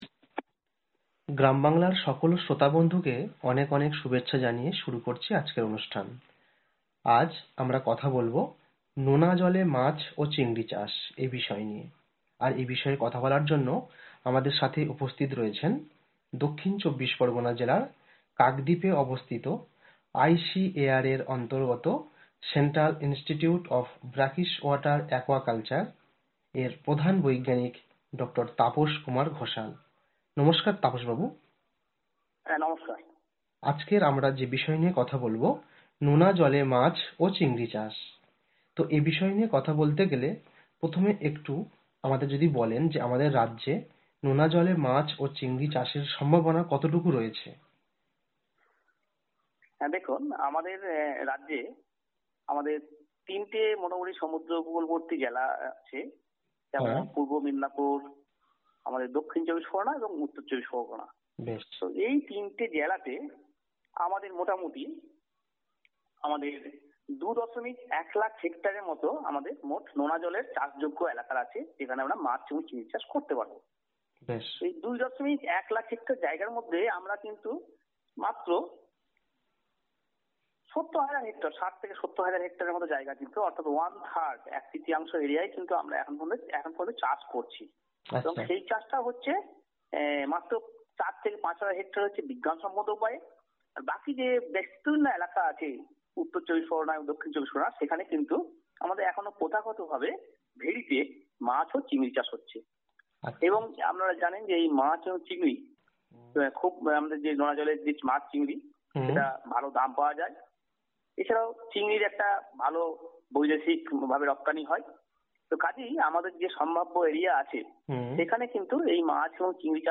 Radio talk